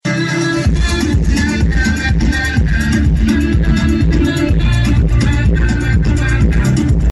Primii tineri veniți din toată țara, care au trecut de filtrele de securitate, spun că abia așteaptă să-și vadă cântăreții preferați și să se distreze.
voxuri-massif.mp3